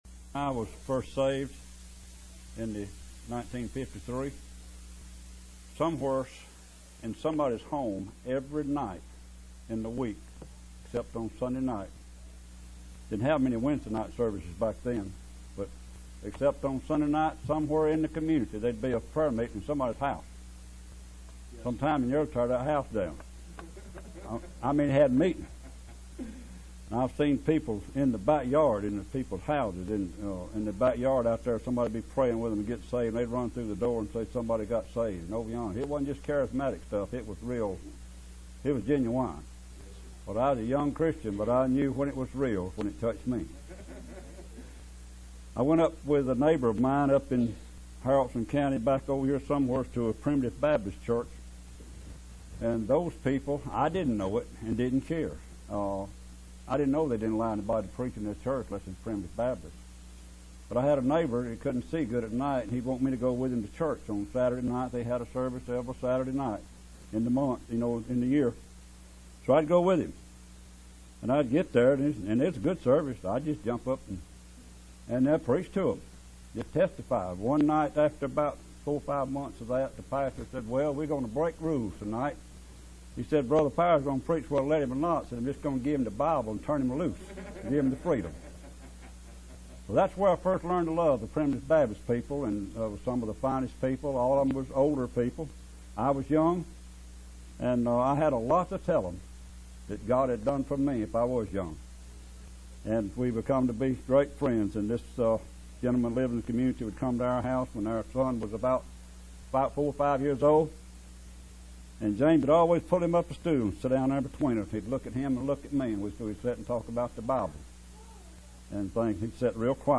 Preached in 2006 Campmeeting at Calvary Baptist Campmeeting in Blountsville, AL.